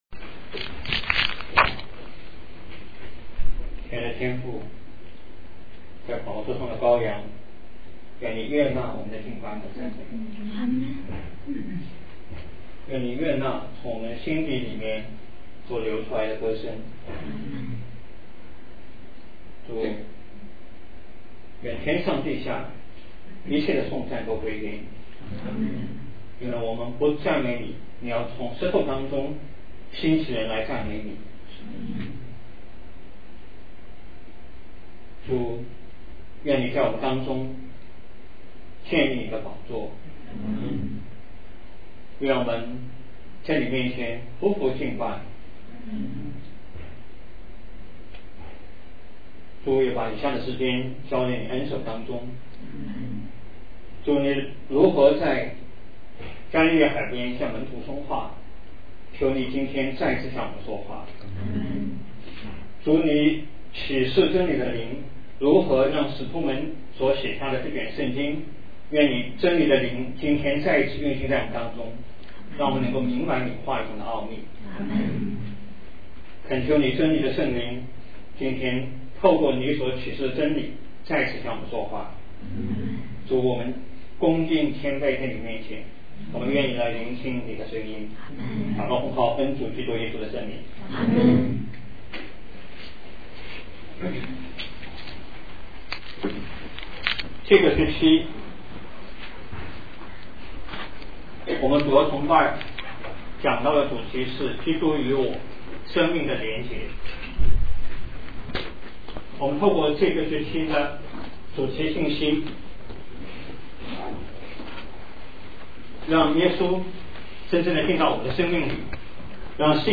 讲章：与主相遇（2006年3月20日，附音频）